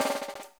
3B SN ROLL-L.wav